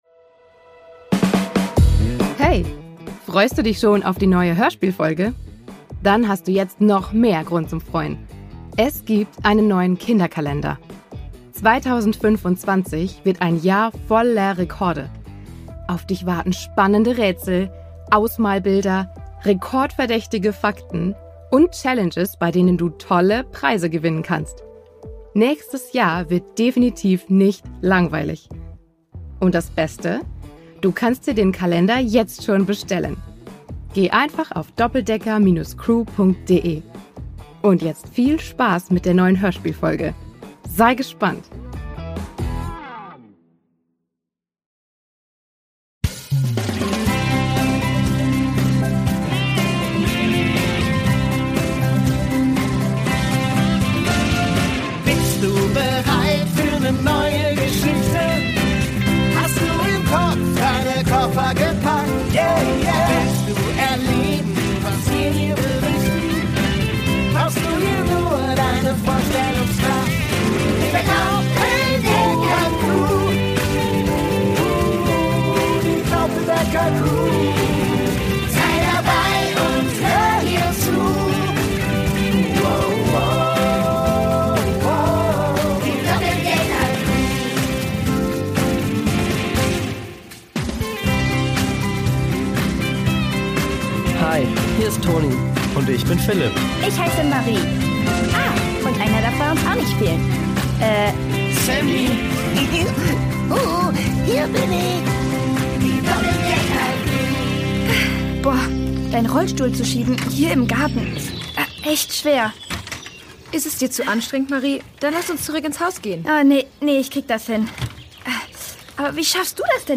Hörspiel für Kinder (Hörbuch)